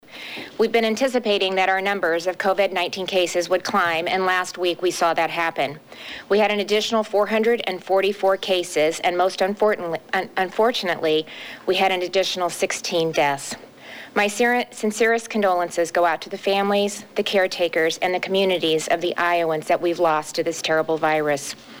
Iowa Governor Kim Reynolds held her daily press briefing today earlier than normal, and will continue to do so each weekday at 11-a.m. During her update this morning, she said “Iowa’s fight against COVID-19 is real…and the last week has been especially hard.”